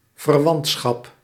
Ääntäminen
Synonyymit familiebetrekking maagschap affiniteit Ääntäminen Haettu sana löytyi näillä lähdekielillä: hollanti Käännös Ääninäyte Substantiivit 1. affinity 2. relationship US 3. kinship Suku: f .